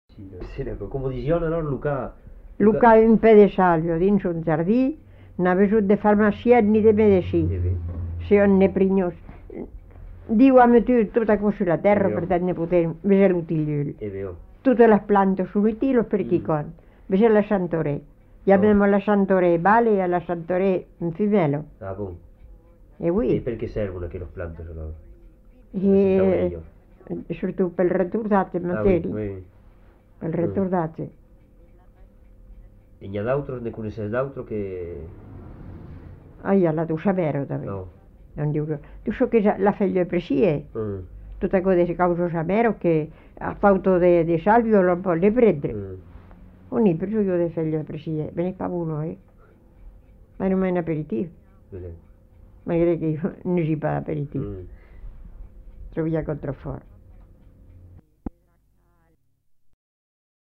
Aire culturelle : Haut-Agenais
Lieu : Fumel
Genre : forme brève
Type de voix : voix de femme